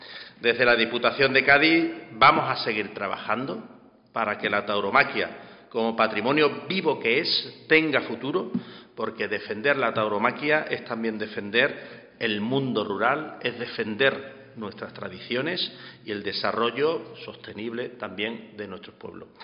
Los detalles de este evento taurino han sido ofrecidos en la Diputación, en rueda de prensa, por el vicepresidente primero, Juancho Ortiz